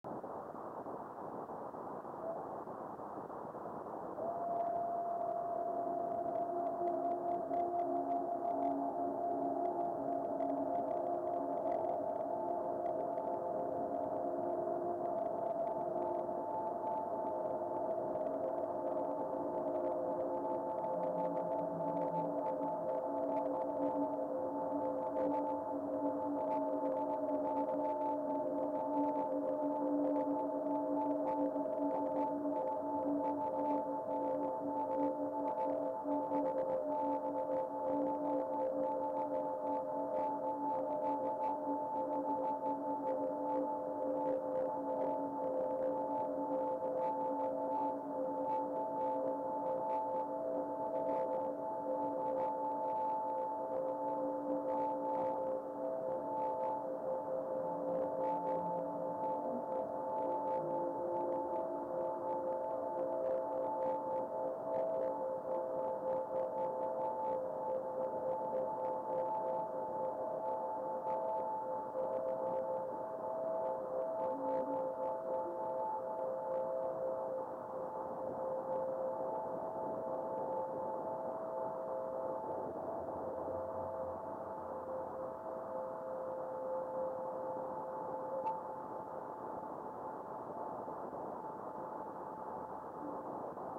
Sonic Movie 2 : Full duration of radio reflection.
The radio reflection off the fireball's ionization was strong and long lasting.